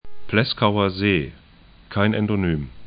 Pleskauer See 'plɛskauɐ'ze: Pihkva järv 'pɪçkva jɛrf et